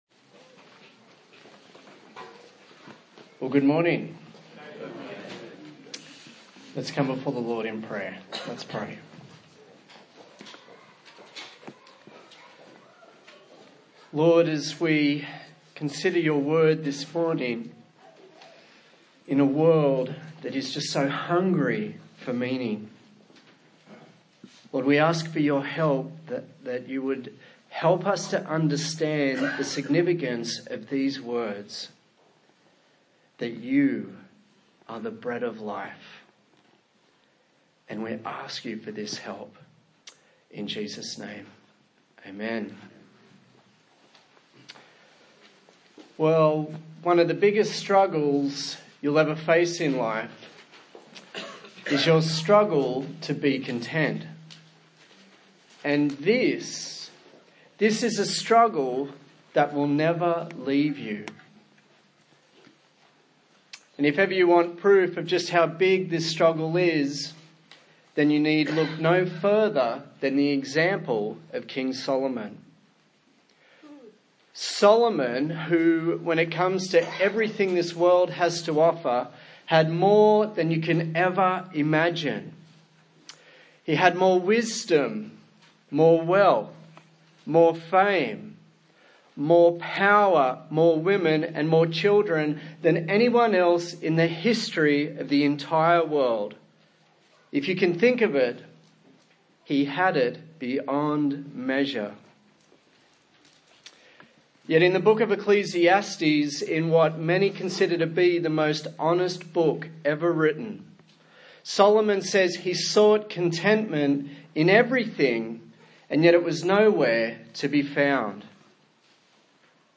Service Type: Sunday Morning A sermon in the series on the book of John